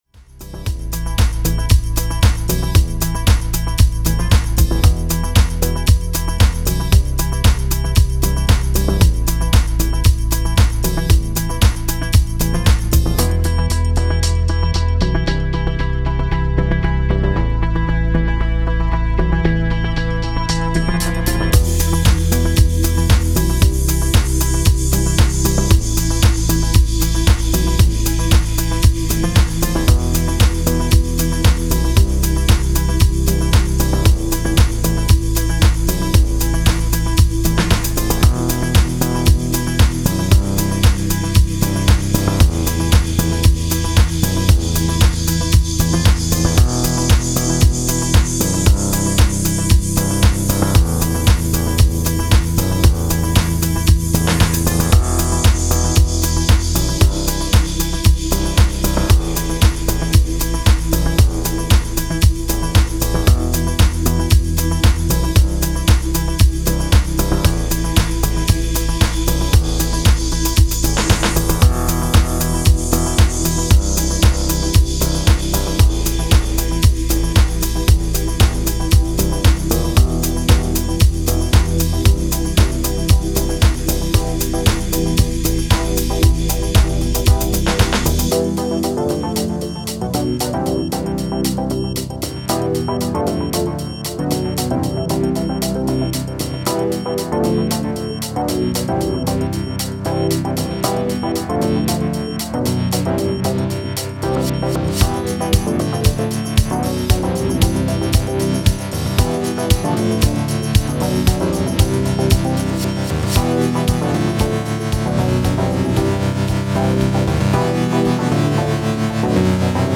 Style: Tech House / Deep House